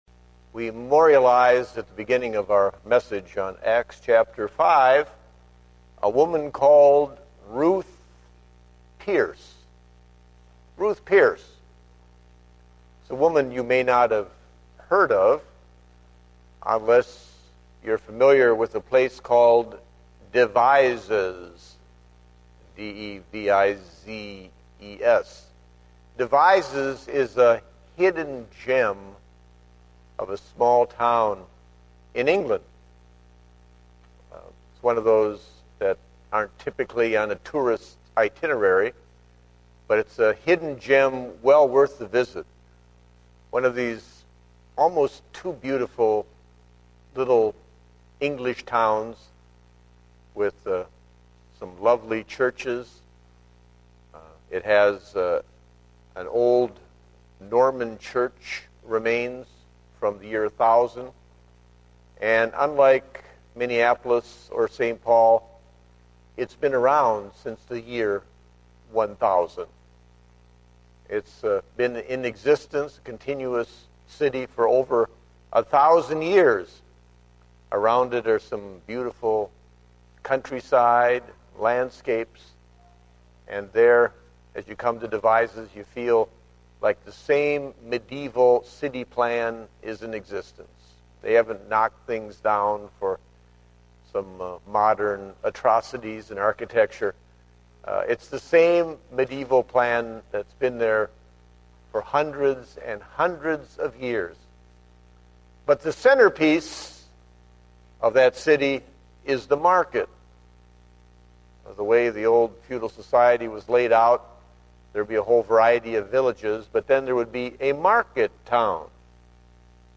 Date: June 27, 2010 (Morning Service)